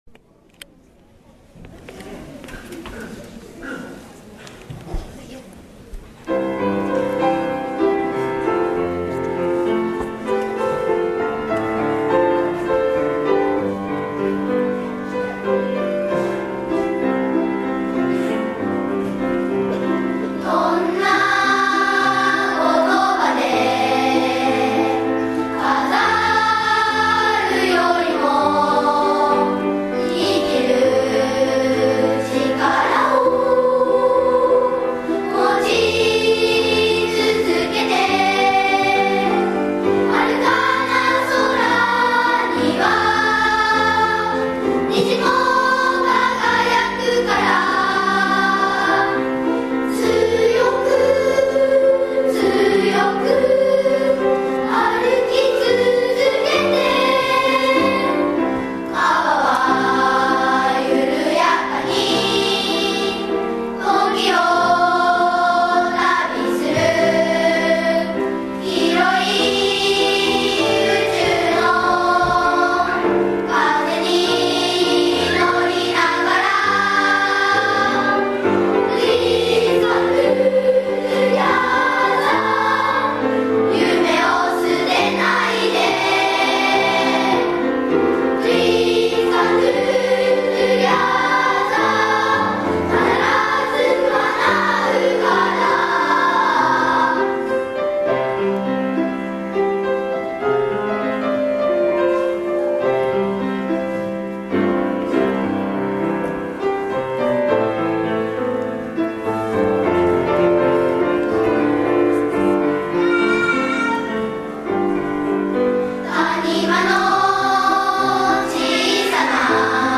１１月１７日（土）平成３０年度校内歌声発表会が行われました。
子どもたちは練習の成果を発揮して精一杯歌いました。